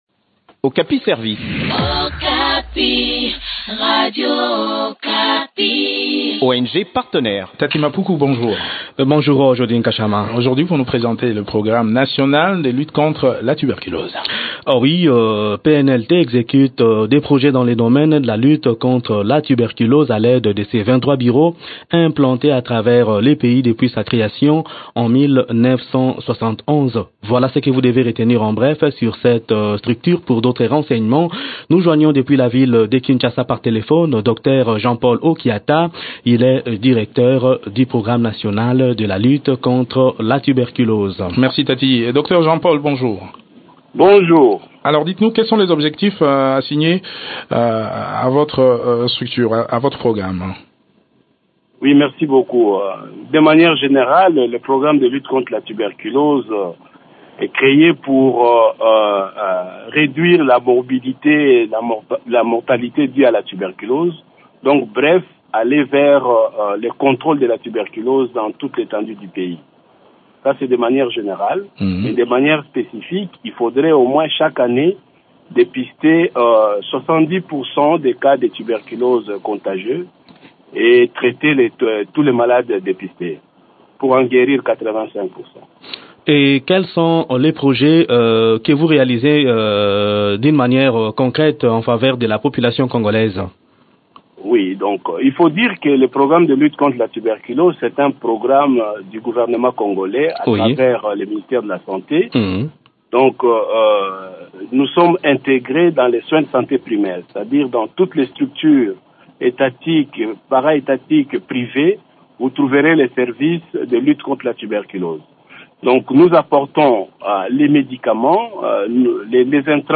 Le point des activités de cette structure dans cet entretien